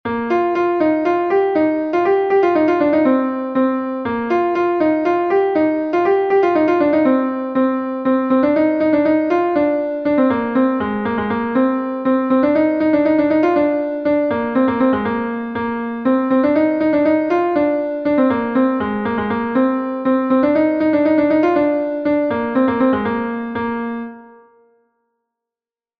Gavotenn Leuelan III is a Gavotte from Brittany